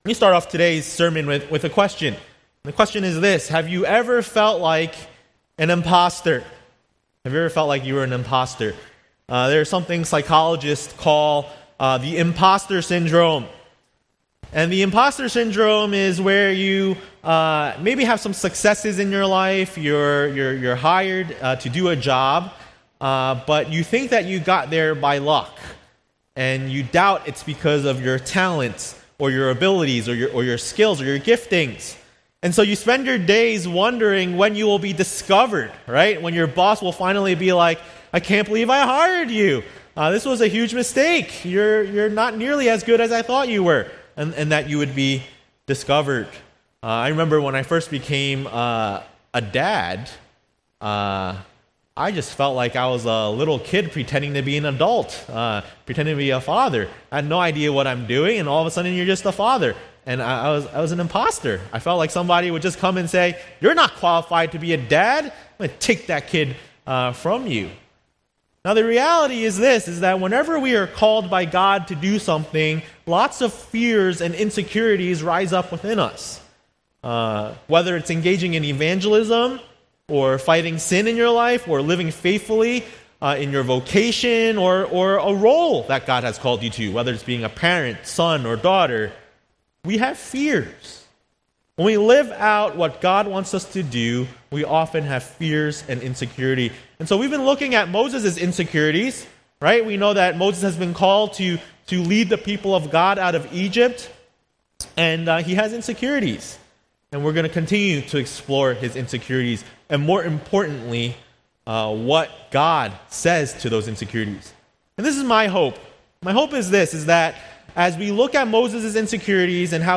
A message from the series "Sojourners: Exodus ."